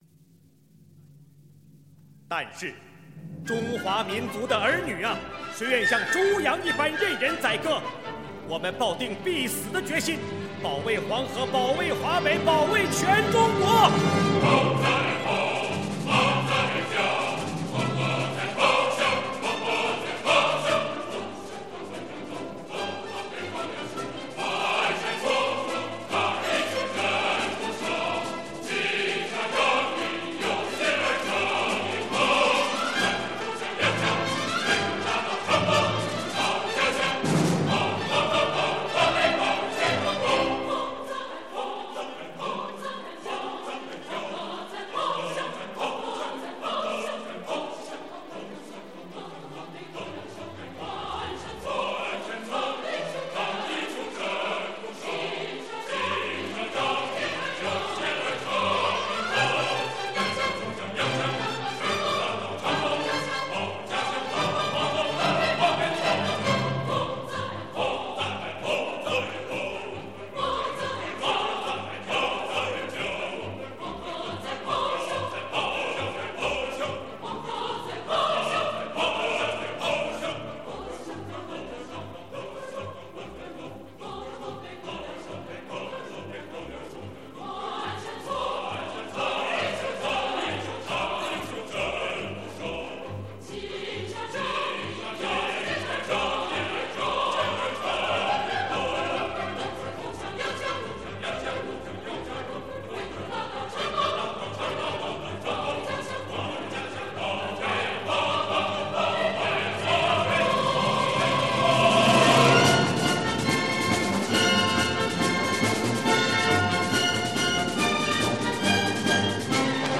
歌曲采用齐唱、轮唱的演唱形式，具有广泛的群众性，是抗日军民广为传播的一首歌。
中国国家交响乐团合唱团 - 保卫黄河.mp3 《保卫黄河》是《黄河大合唱》的第七乐章，由光未然、冼星海所创写成于中国抗日战争时期。
这首歌采用了民间打击乐节奏和广东狮子舞音乐旋律为素材，使歌曲显得明快、豪放、音乐形象分外鲜明，并具有浓厚的民族风格。 全曲采用了进行曲体裁、以短促跳动、振奋人心的音调，响亮的战斗口号，铿锵有力的节奏，以快速大跳的动机和逐步扩张的音型，使歌曲充满力量的感情，形象地刻画了游击健儿端起土枪洋枪、挥动大刀长矛，在青纱帐里、万山丛中，为保卫黄河、保卫全中国而战斗的壮丽场景。